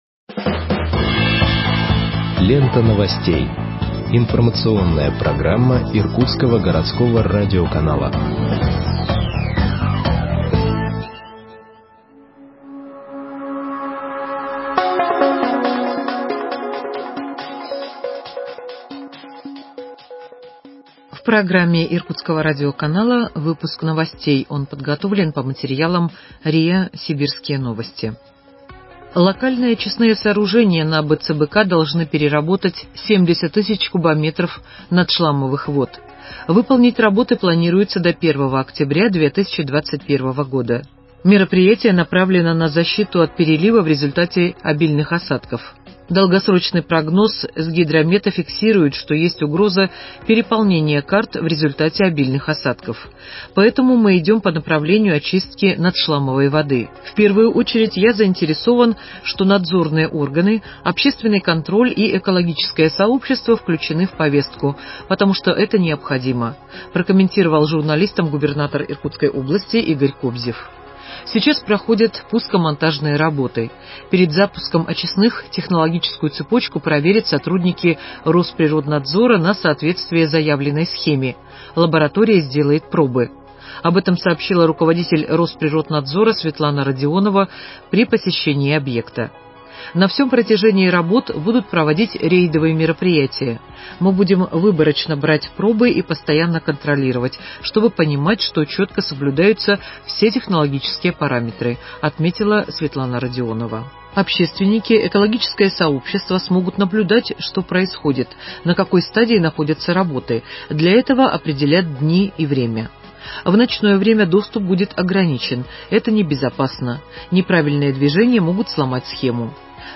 Выпуск новостей в подкастах газеты Иркутск от 21.05.2021 № 2